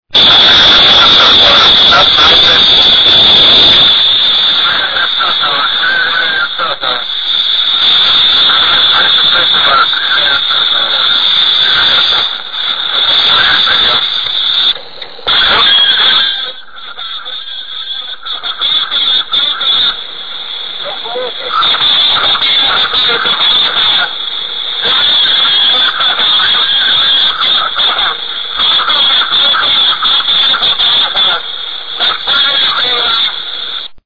Despite its age (34 years old) its sound quality is OK.
There are also some strange voice signals with someone shouting
The counterstation could be heard saying that he had a very bad reception.
sokolcalls.mp3